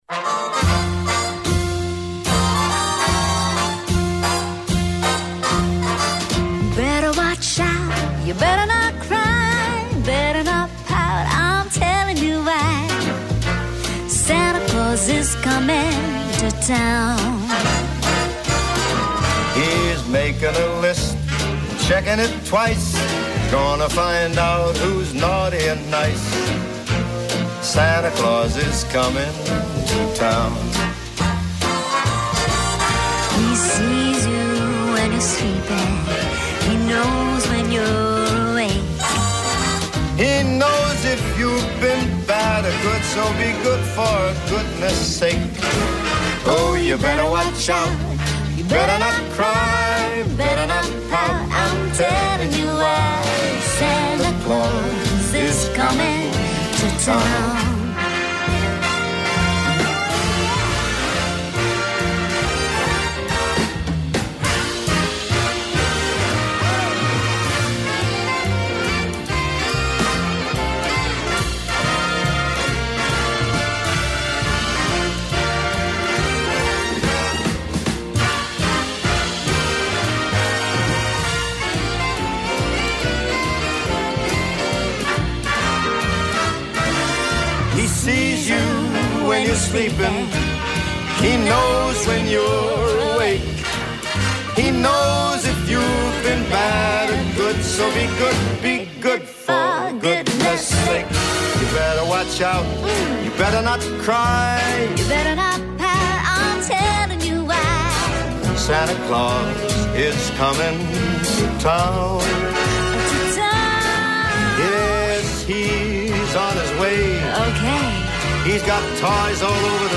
Ακούστε την εκπομπή του Νίκου Χατζηνικολάου στον ραδιοφωνικό σταθμό RealFm 97,8, την Πέμπτη 18 Δεκεμβρίου 2025.